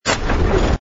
engine_bw_freighter_start.wav